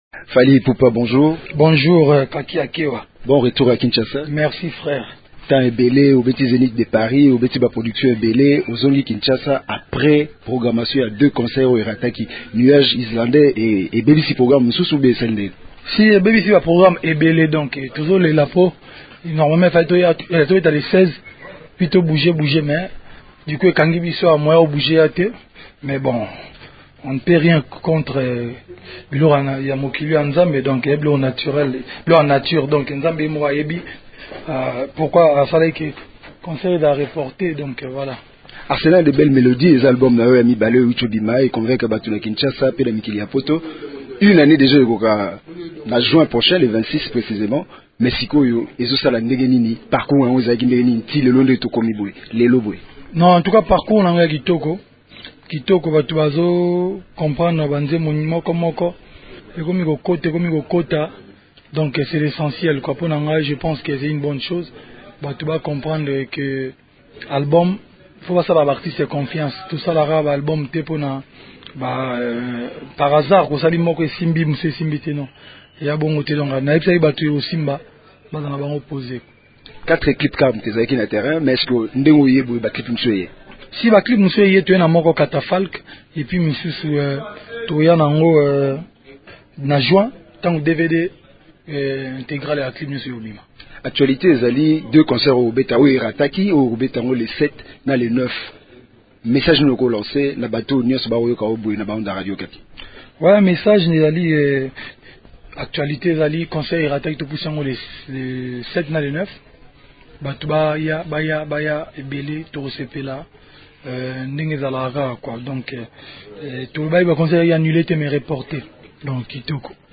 Entretien à bâtons rompus.